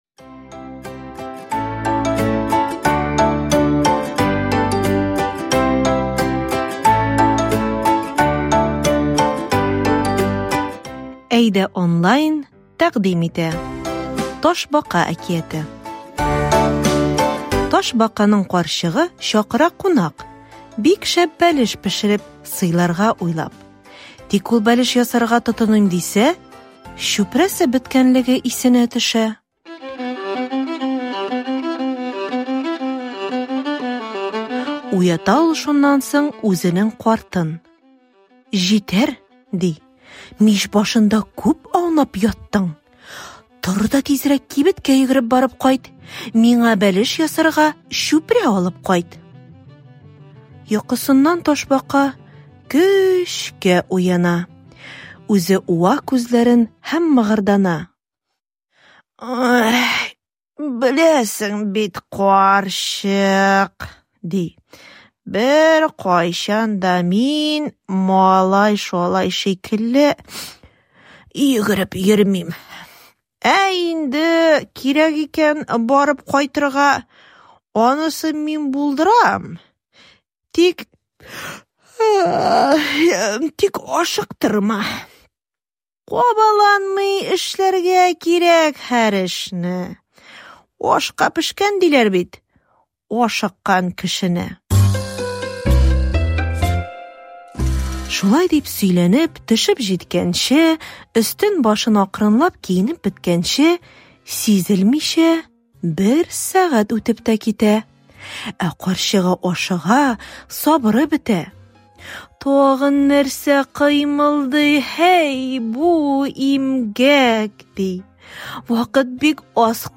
Каждый текст озвучен для аудирования.